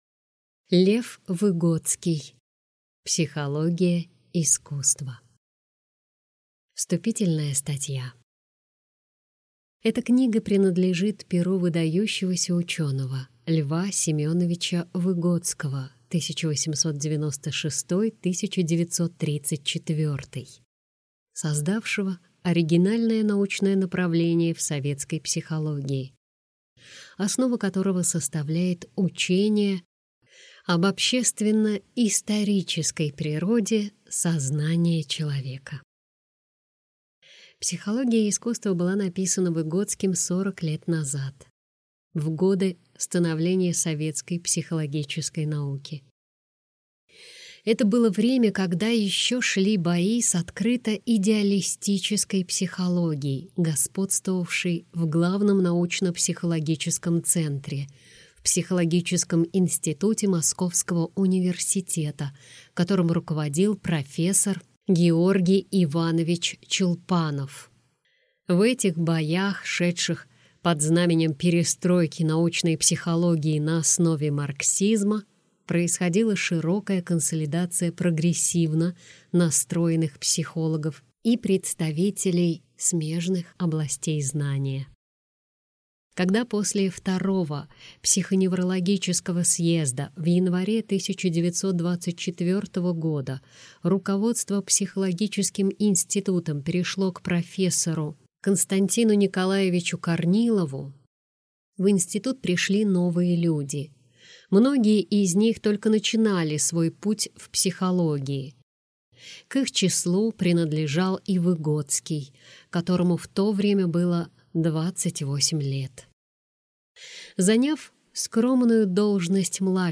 Аудиокнига Психология искусства | Библиотека аудиокниг